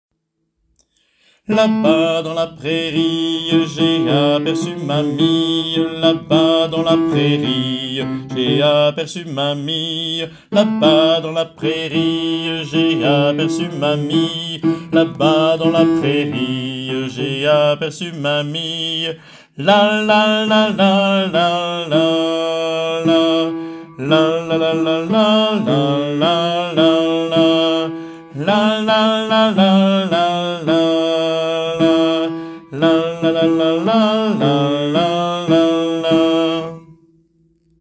Téléchargez la voix des basses
la-bas-dans-la-prairie-alti-bassi-p3X5HBsk32mLCKq0.mp3